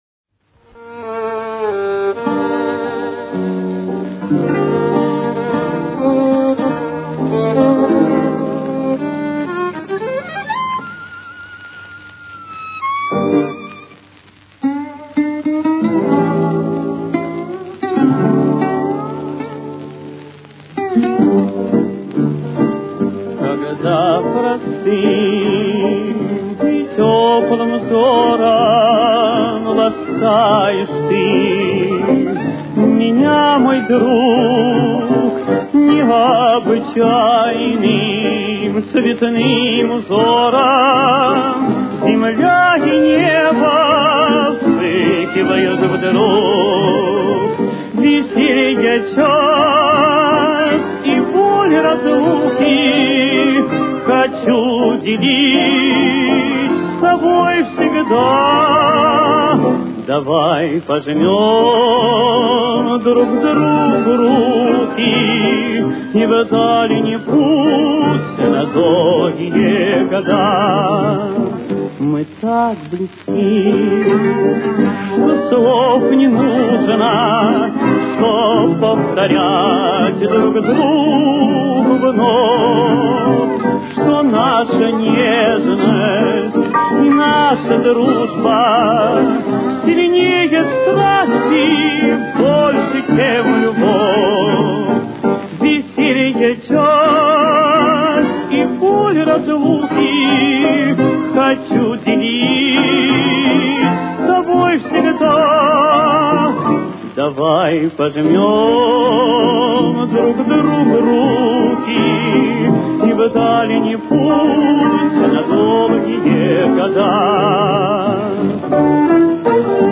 с очень низким качеством (16 – 32 кБит/с)
Темп: 82.